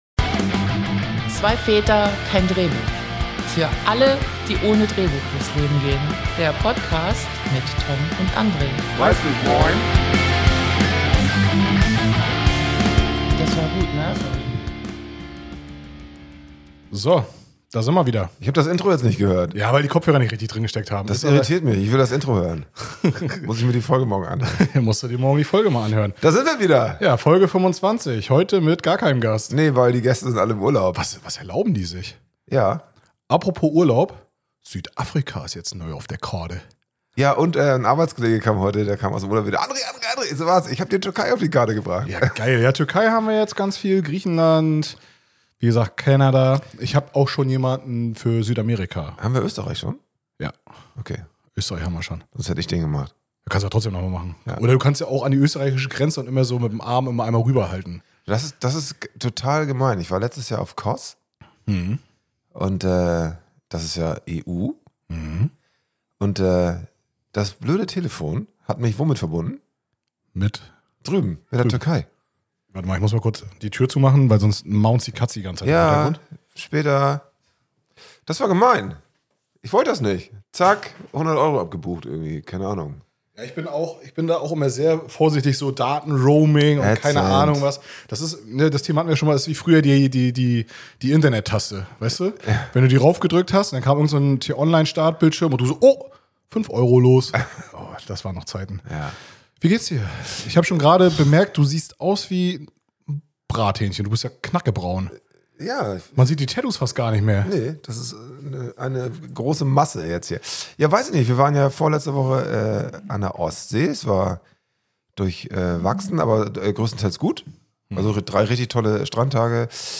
In dieser Folge quatschen wir ohne Gast über Sommer, Urlaube und kleine Alltagsabenteuer.